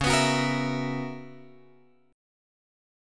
C# Minor 13th